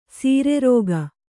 ♪ sīre rōga